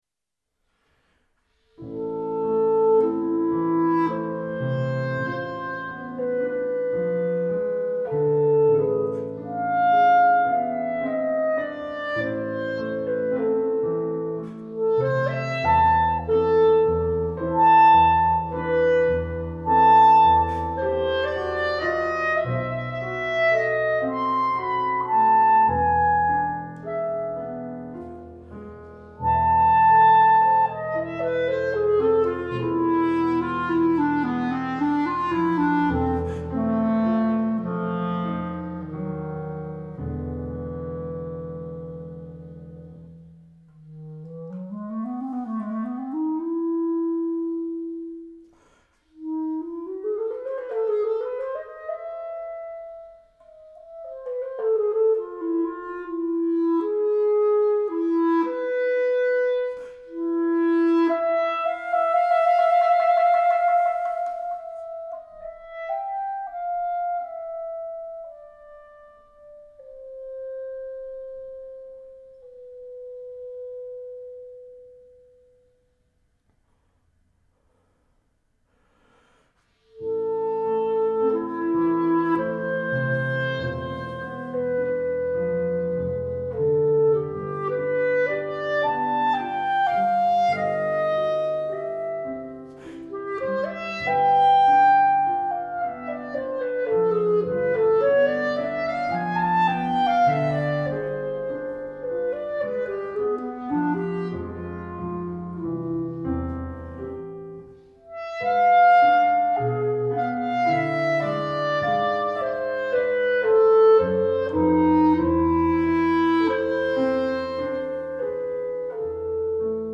Voicing: Clarinet Method w/ Audio